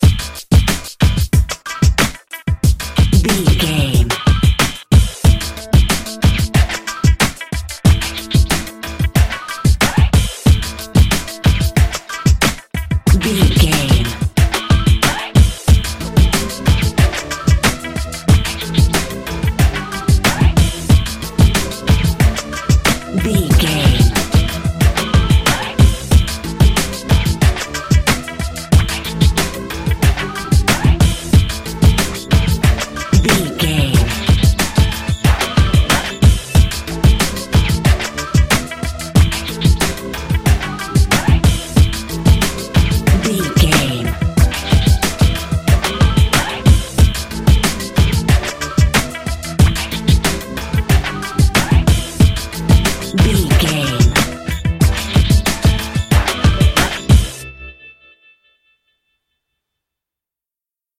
Aeolian/Minor
drum machine
synthesiser
percussion